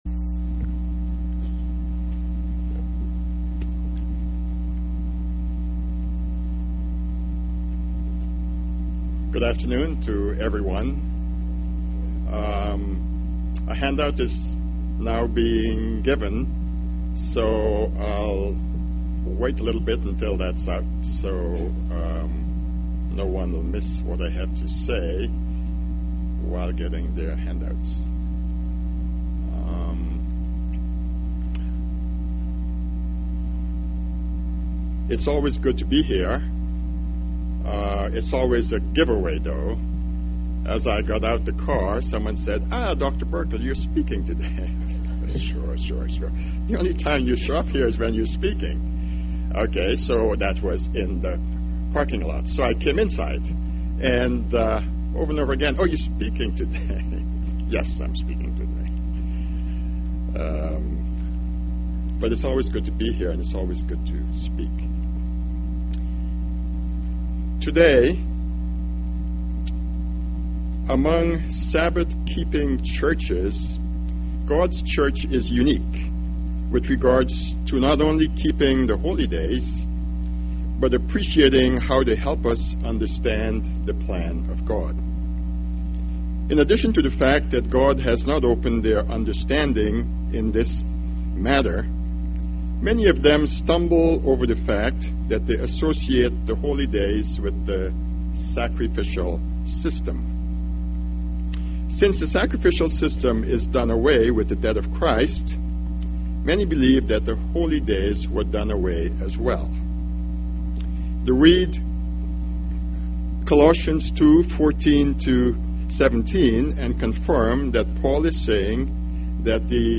Given in Tampa, FL
UCG Sermon Studying the bible?